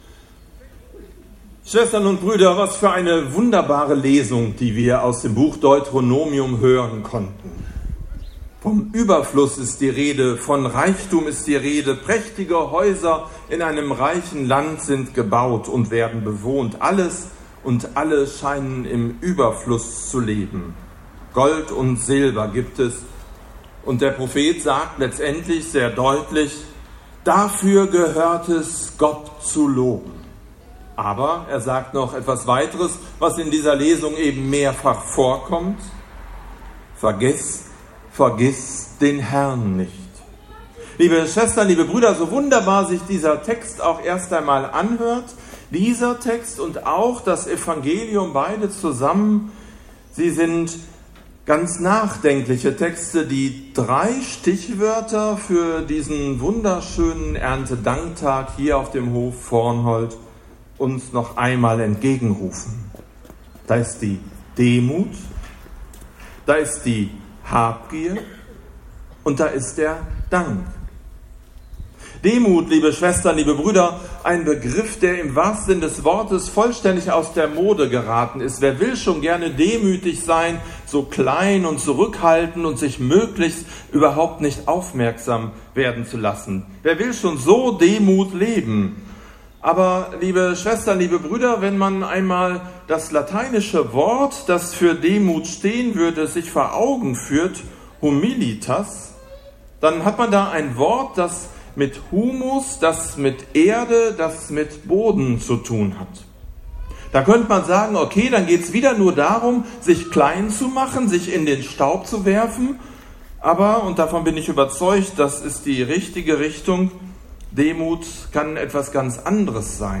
Predigt zum Erntedank 2018 – St. Nikolaus Münster
2018_Predigt_Erntedank_Plädoye_demütige_großzügige_Kirche.mp3